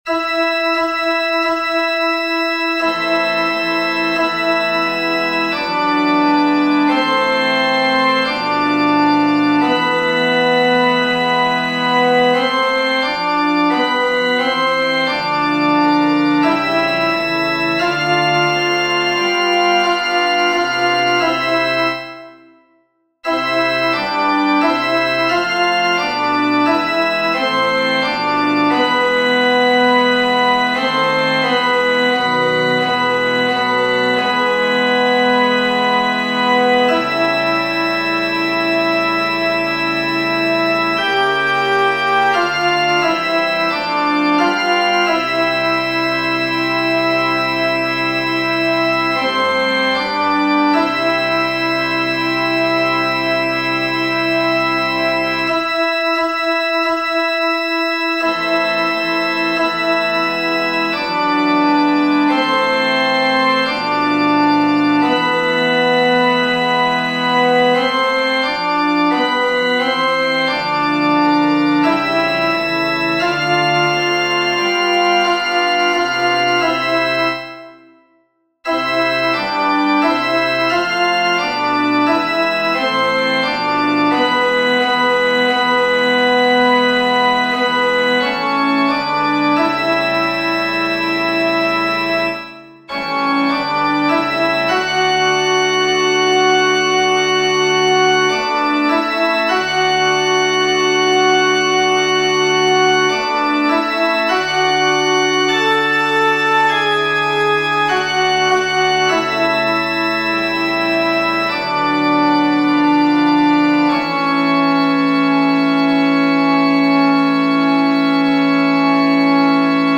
Modlitba-1T.mp3